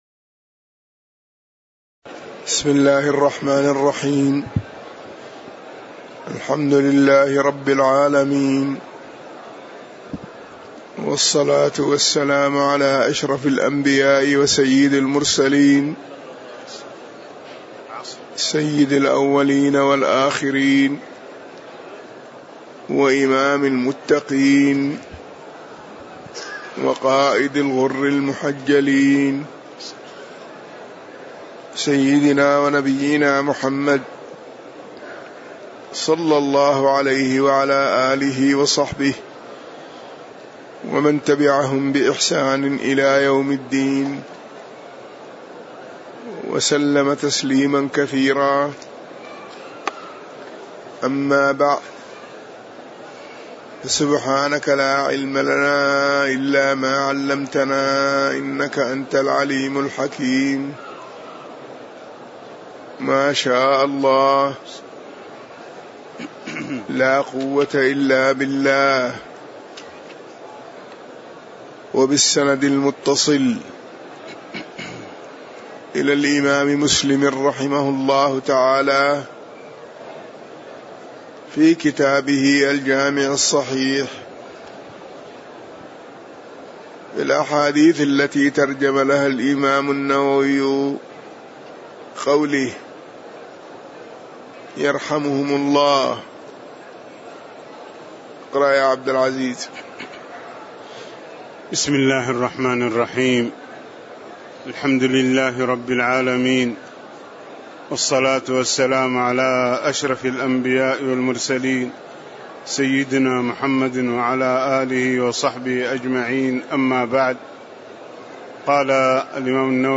تاريخ النشر ٥ رمضان ١٤٣٨ هـ المكان: المسجد النبوي الشيخ